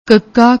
sixth letter of Punjabi script used to express velar plosive unaspirated sound [k]